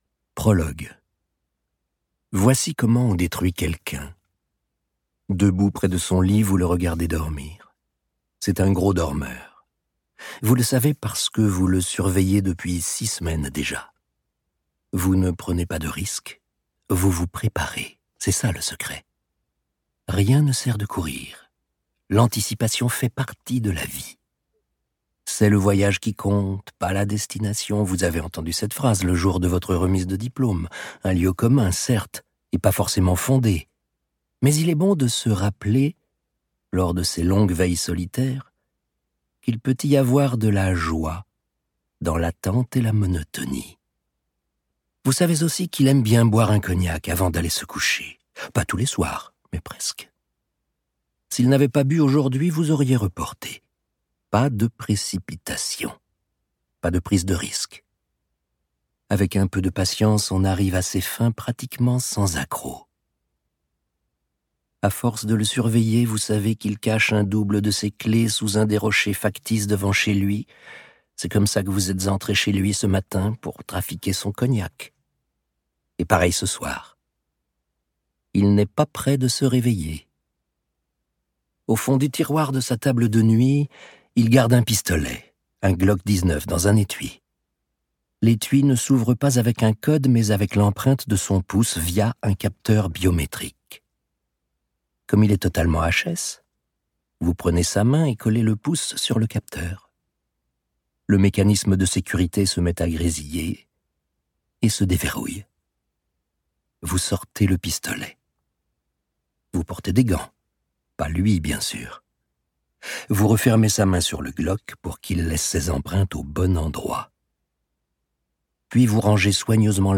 Extrait gratuit - Méfie-toi de Harlan COBEN